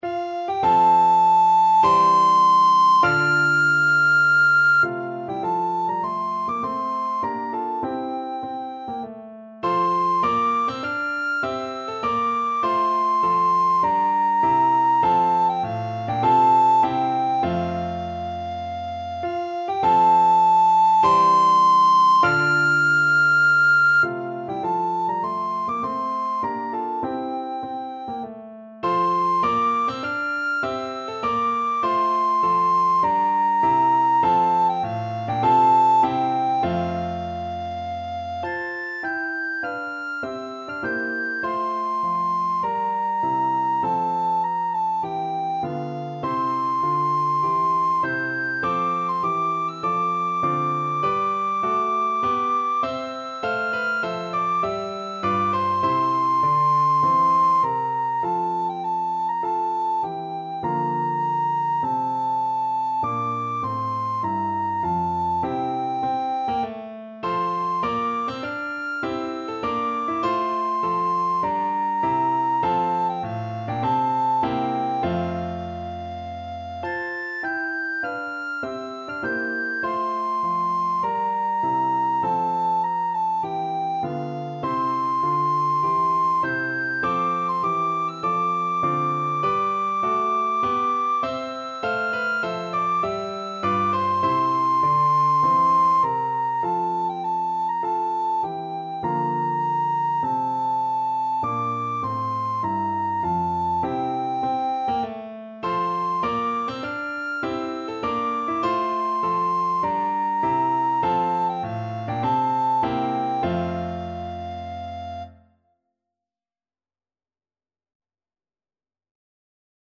Free Sheet music for Soprano (Descant) Recorder
F major (Sounding Pitch) (View more F major Music for Recorder )
4/4 (View more 4/4 Music)
Classical (View more Classical Recorder Music)